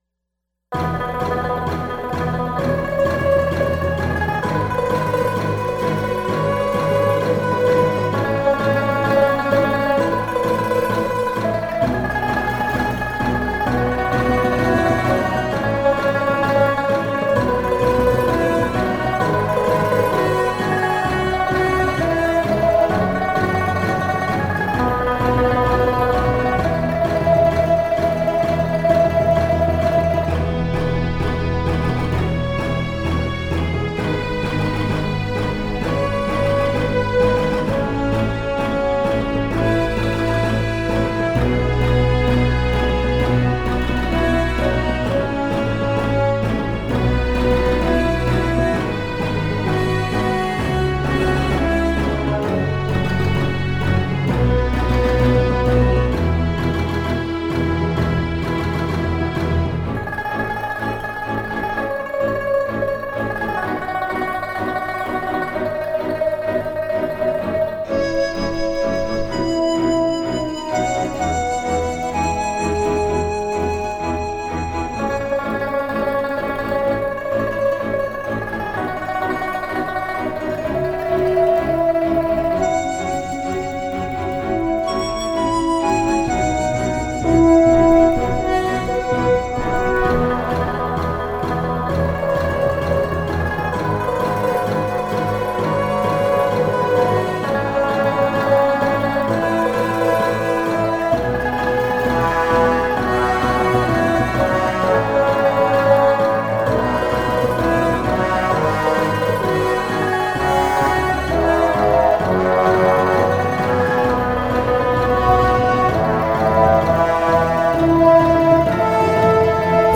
為了體現大唐帝國融合東西文化的壯大恢弘，本首音樂以雄渾的西洋管弦樂，搭配中國風的琵琶共同演出。